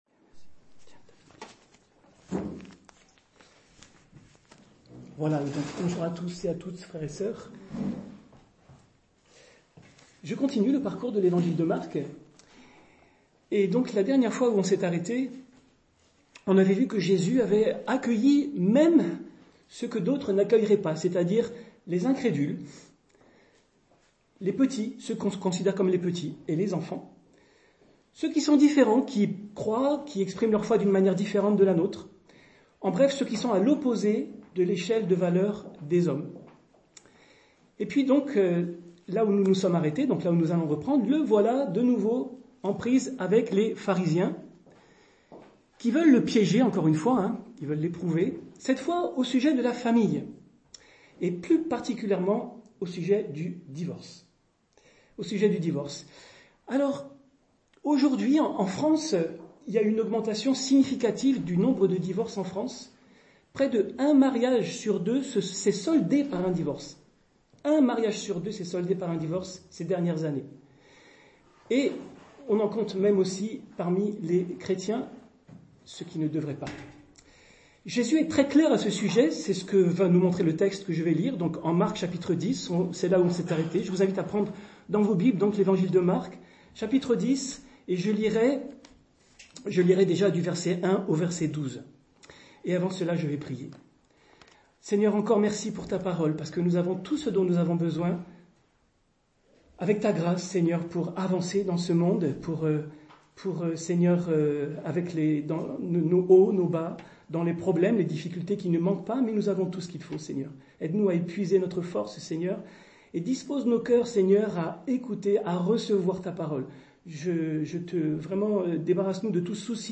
Culte du dimanche 7 septembre 2024 - EPEF